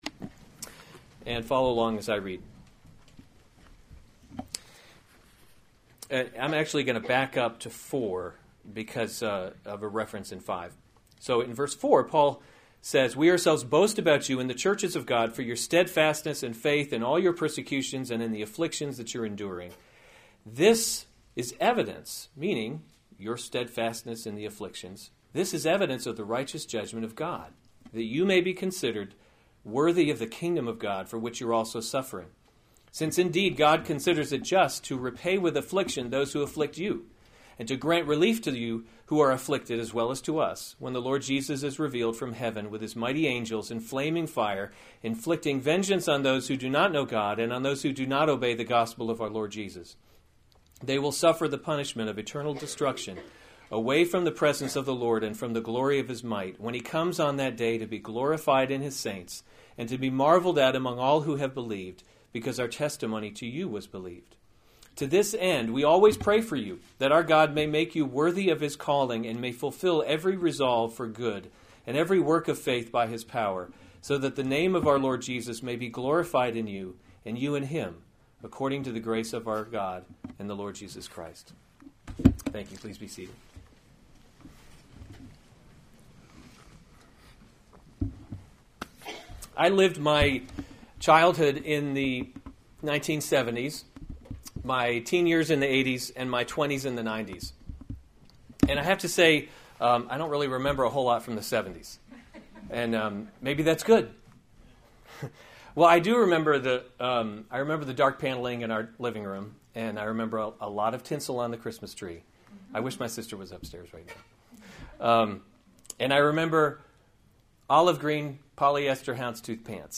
May 14, 2016 2 Thessalonians – The Christian Hope series Weekly Sunday Service Save/Download this sermon 2 Thessalonians 1:5-12 Other sermons from 2 Thessalonians The Judgment at Christ’s Coming 5 This […]